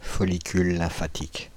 Ääntäminen
Synonyymit nodule lymphoïde Ääntäminen France (Île-de-France): IPA: /fɔ.li.kyl lɛ̃.fa.tik/ Haettu sana löytyi näillä lähdekielillä: ranska Käännöksiä ei löytynyt valitulle kohdekielelle.